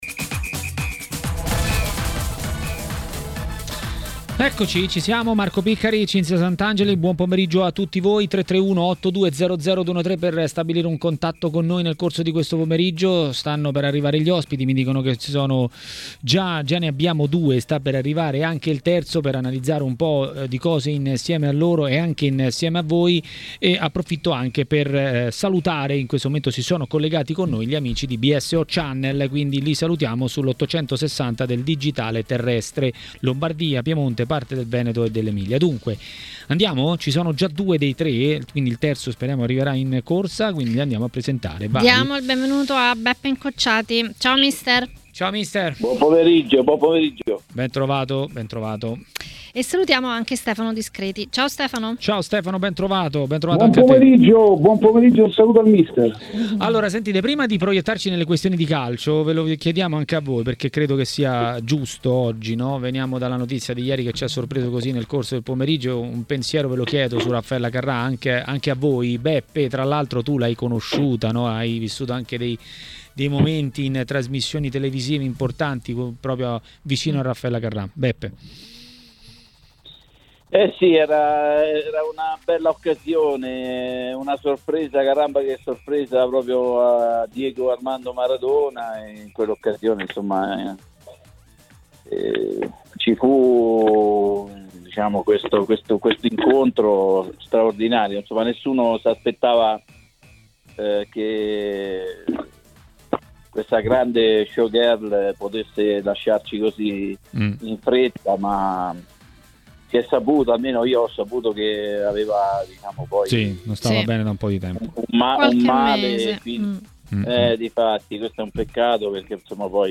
A parlare di Juventus a Maracanà, nel pomeriggio di TMW Radio,